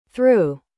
2. Through (θruː) Tradução: Através.